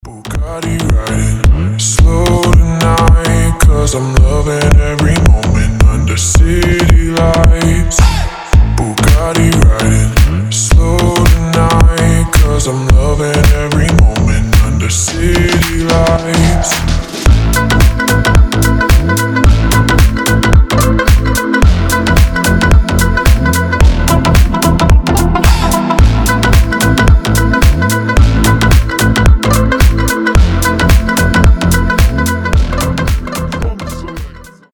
• Качество: 320, Stereo
deep house
slap house